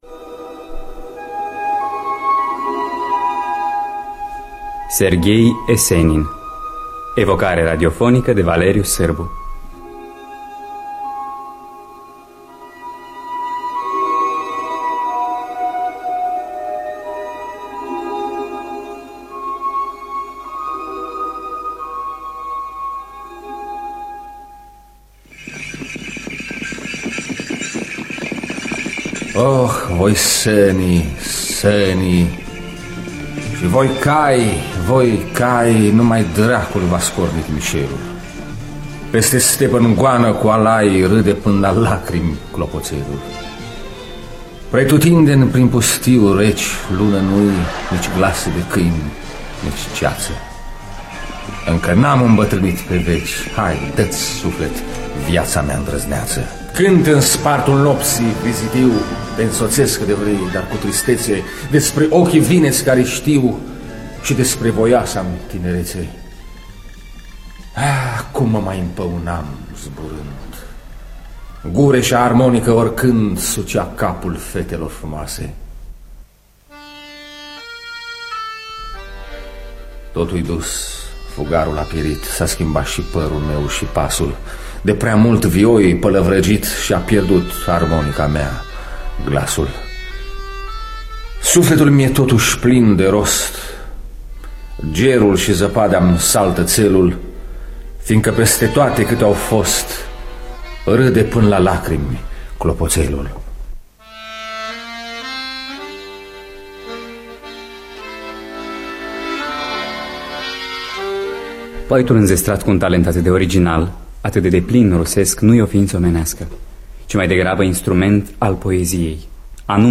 Scenariu radiofonic